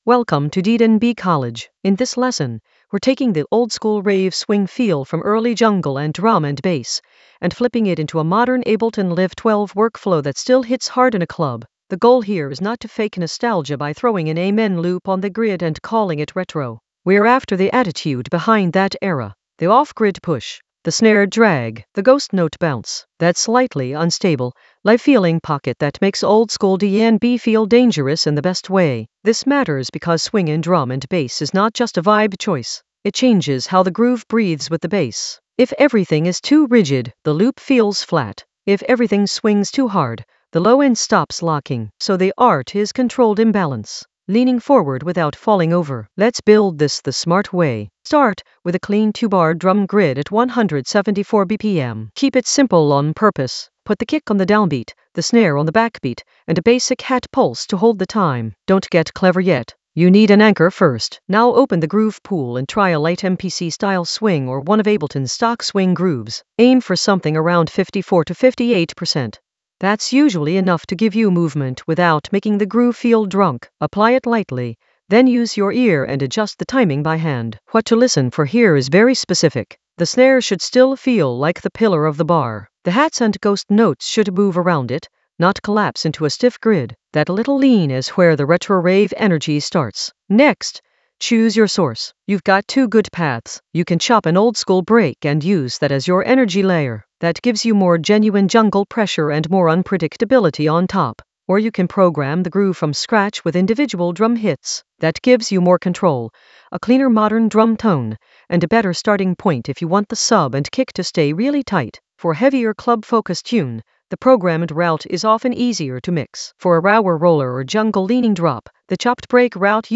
An AI-generated intermediate Ableton lesson focused on Retro Rave approach: oldskool DnB swing flip in Ableton Live 12 in the Workflow area of drum and bass production.
Narrated lesson audio
The voice track includes the tutorial plus extra teacher commentary.